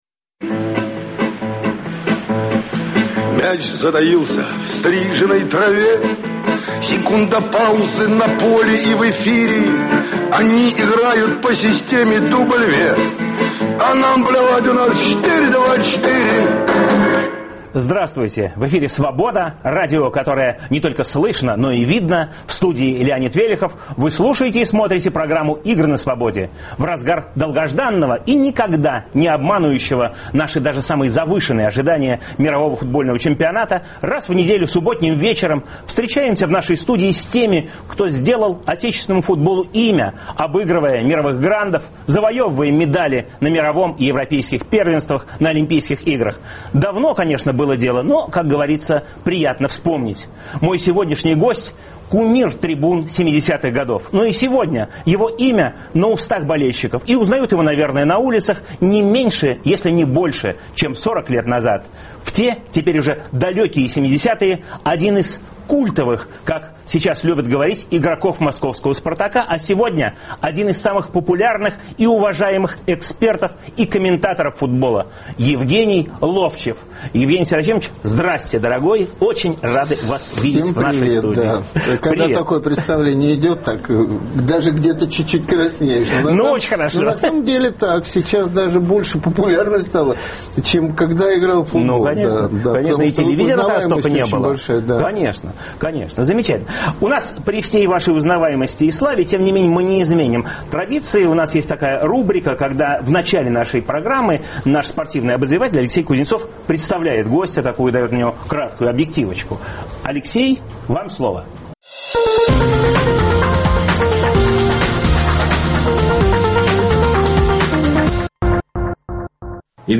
Мастера мирового класса - в студии Свободы.